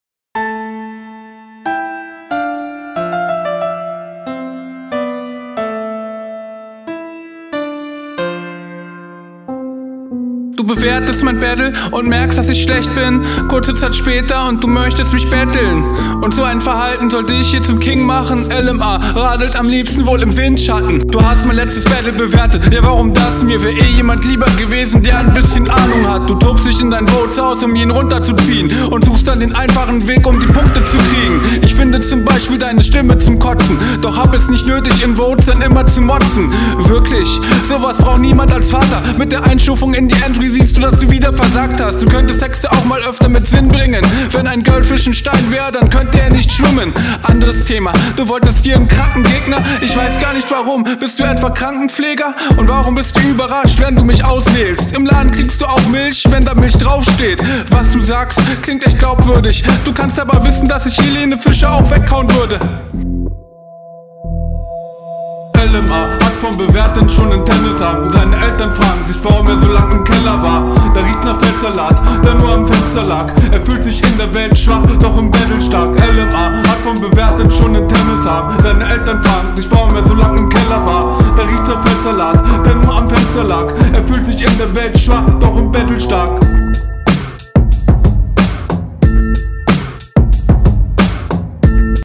Finde du kommst nicht so gut auf dem Beat und ein …